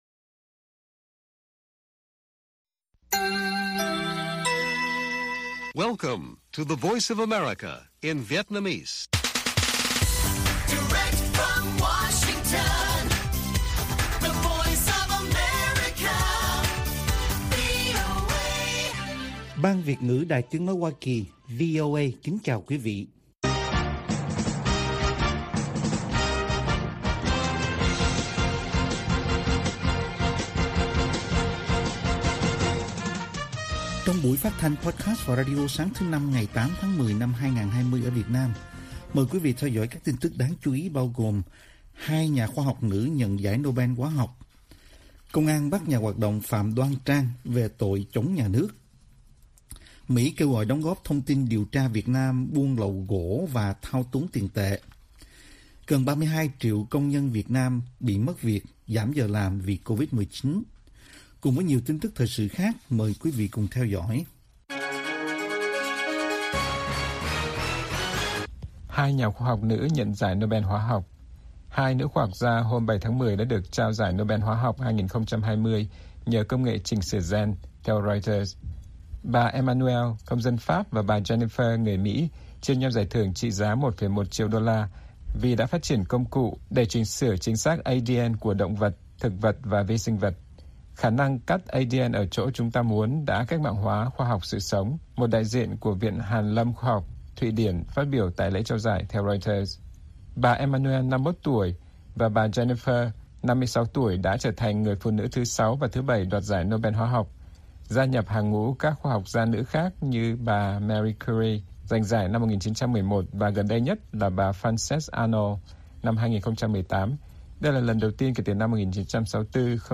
Bản tin VOA ngày 8/10/2020